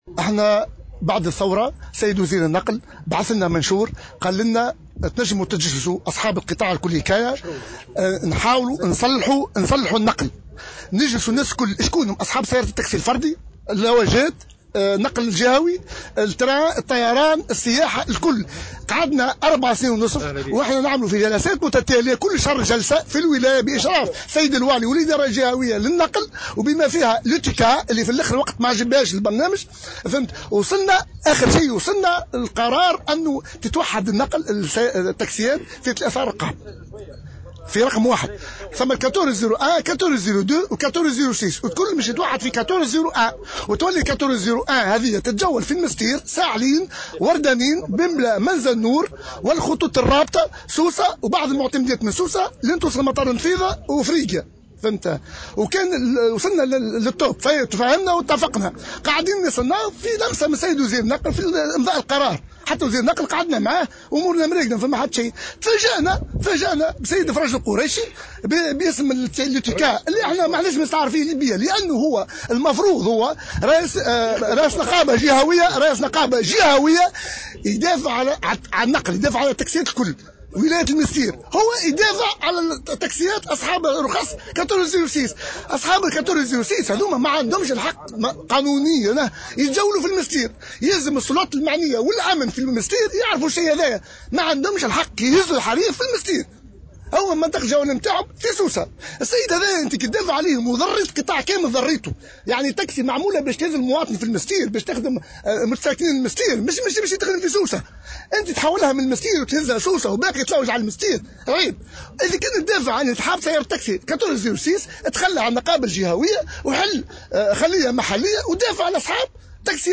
a déclaré au micro de Jawhara FM